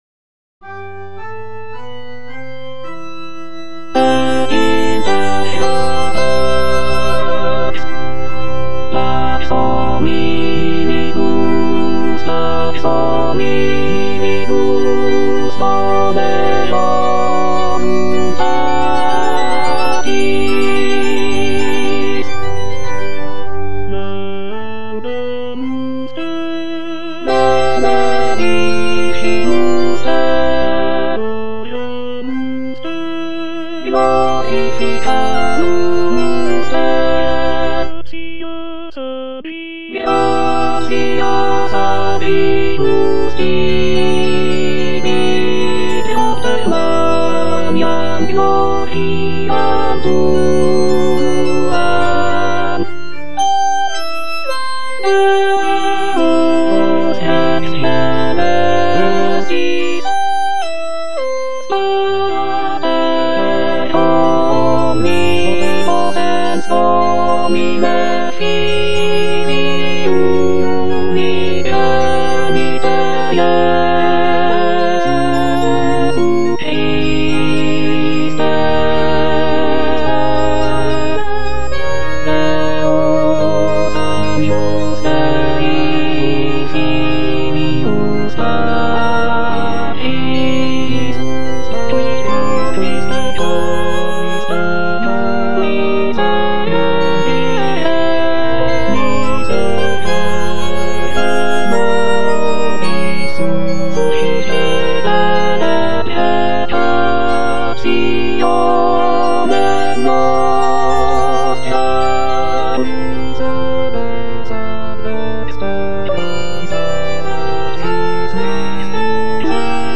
J.G. RHEINBERGER - MASS IN C OP. 169 Gloria - Alto (Emphasised voice and other voices) Ads stop: auto-stop